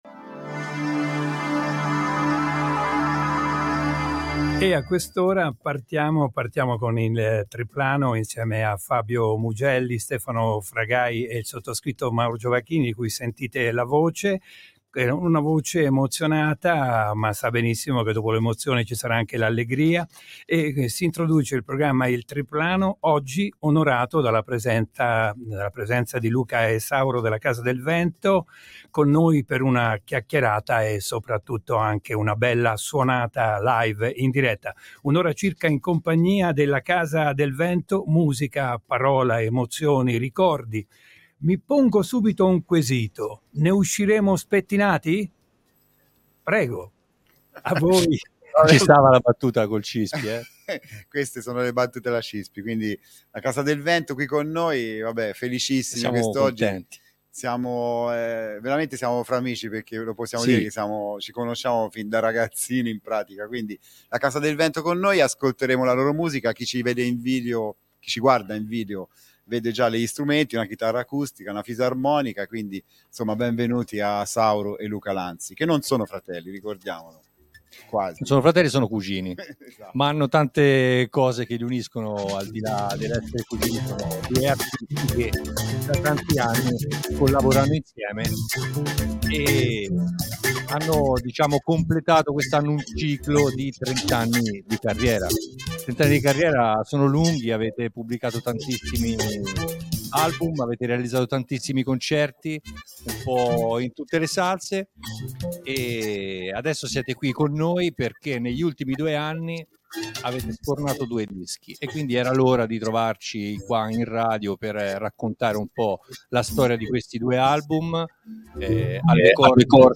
ARTISTI in STUDIO – Casa del Vento ospite nel Biplano – RadioFly
Artisti, musica ed emozioni in studio giovedì 25 Gennaio a RadioFly.
vocalist e chitarra
fisarmonica, quattro live ricchi di musica ma sopratutto di emozioni.